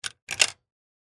На этой странице собраны звуки копилки: от звонкого падения монет до глухого стука накопленных сбережений.
Звон монеты в копилке